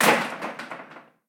Cerrar la puerta de un plató de televisión
Sonidos: Acciones humanas